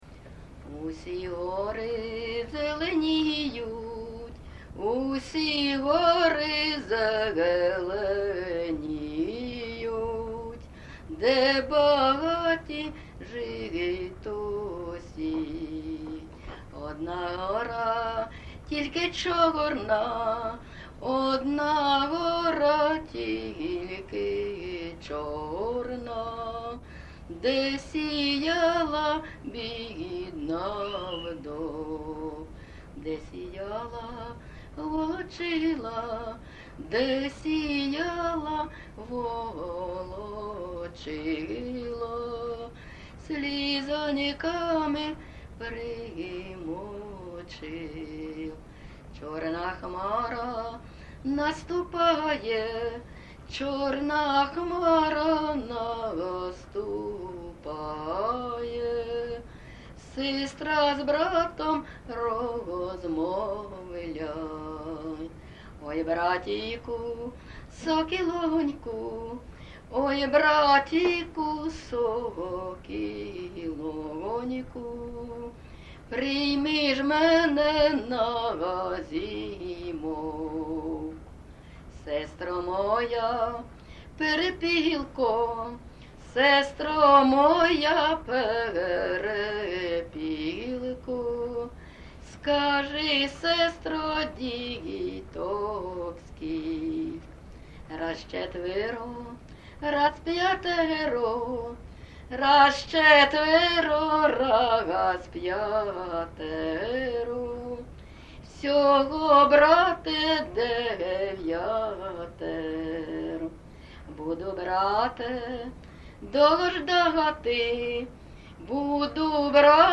ЖанрПісні з особистого та родинного життя
Місце записум. Маріуполь, Донецька обл., Україна, Північне Причорноморʼя